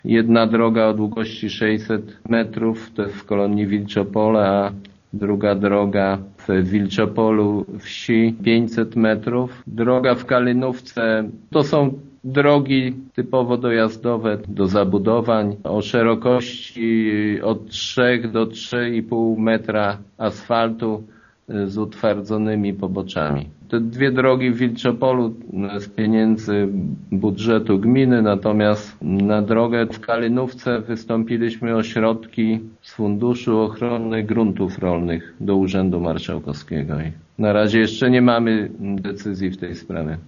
„Najszybciej, do końca lipca, mają być wykonane prace w Kalinówce, a do końca wakacji powinny być gotowe drogi w Wilczopolu i Kolonii Wilczopole” – informuje wójt Jacek Anasiewicz: